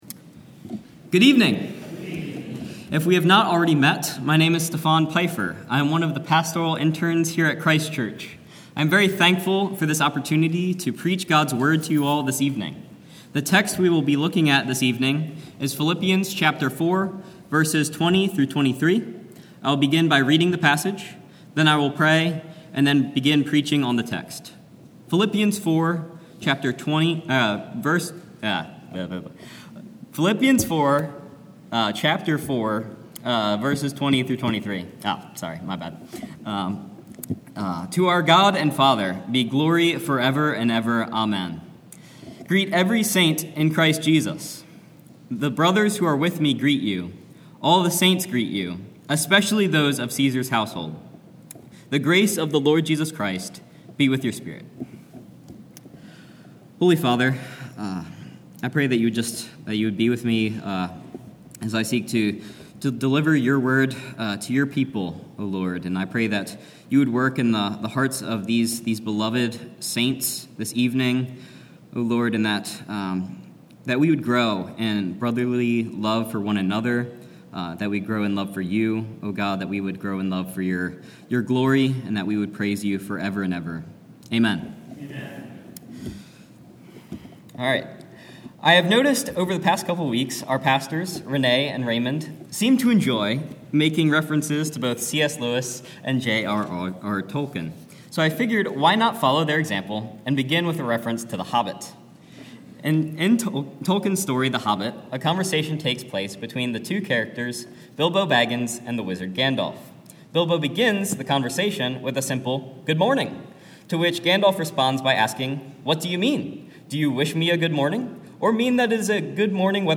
Sermon-68-Even.mp3